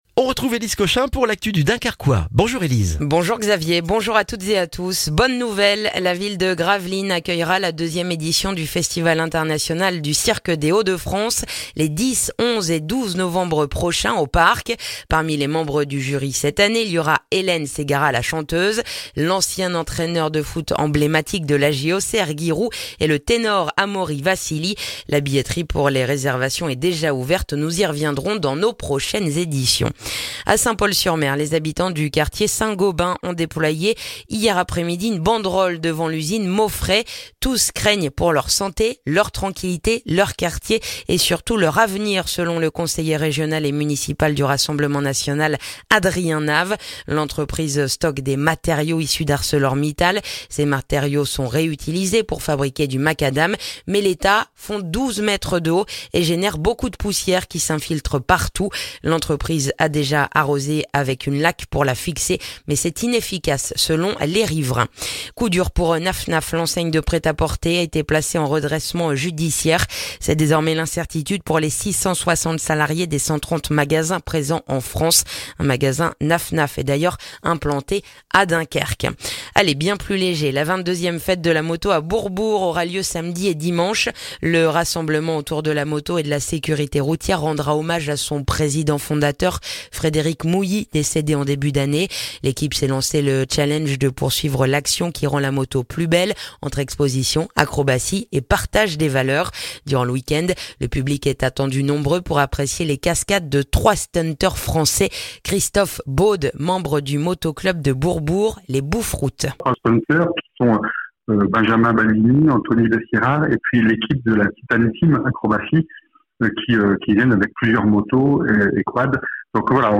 Le journal du jeudi 7 septembre dans le dunkerquois